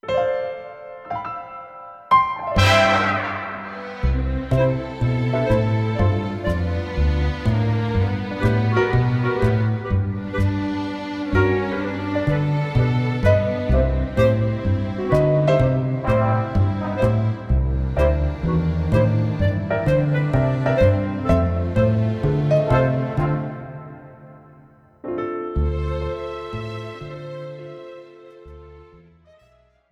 This is an instrumental backing track cover.
• Without Backing Vocals
• No Fade